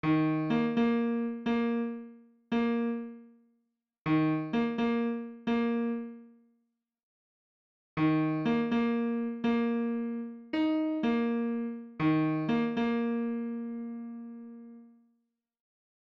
Padillion键独奏
标签： 120 bpm Electronic Loops Piano Loops 2.69 MB wav Key : Unknown
声道立体声